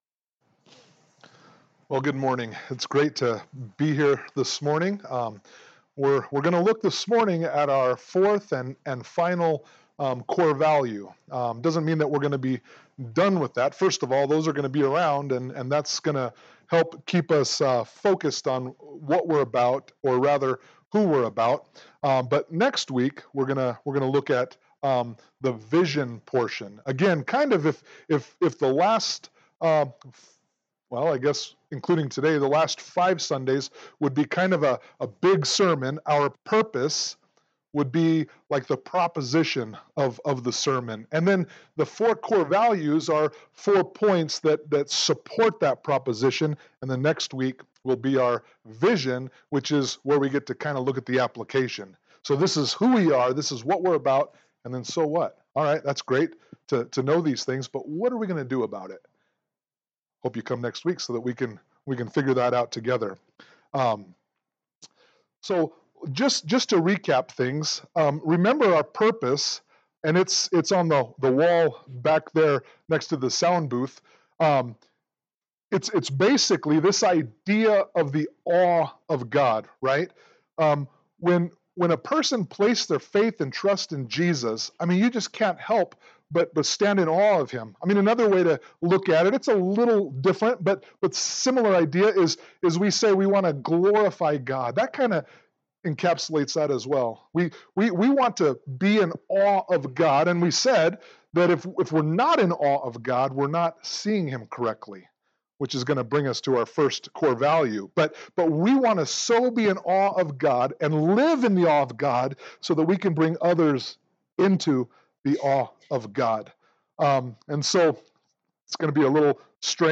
Service Type: Sunday Morning Worship